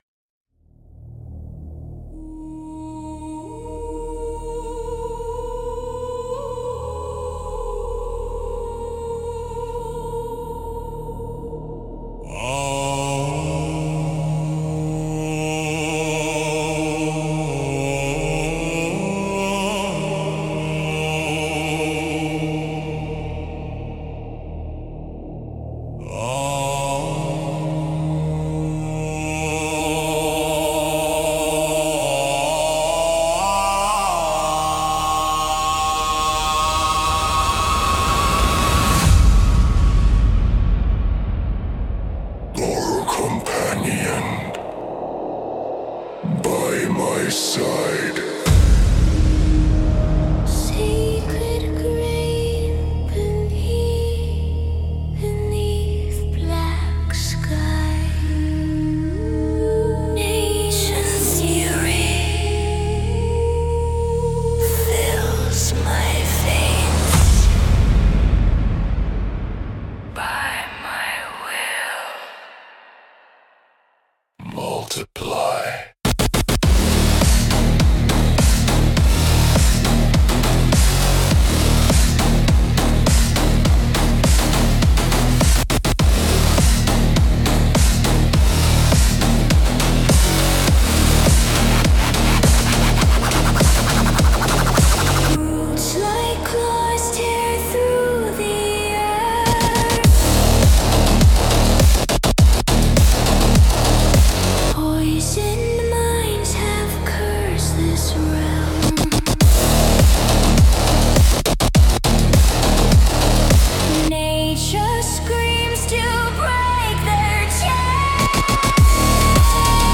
Created through use of AI